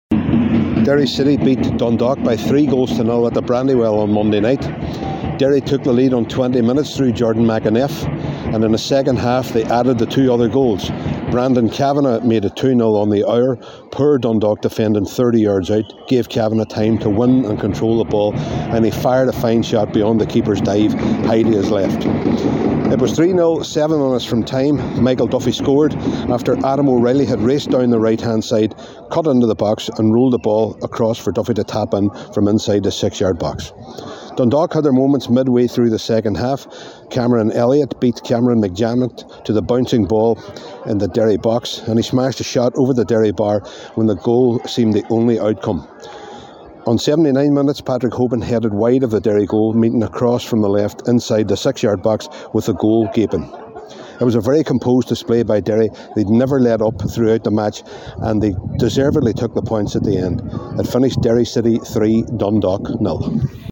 FT Report: Derry City 3 Dundalk 0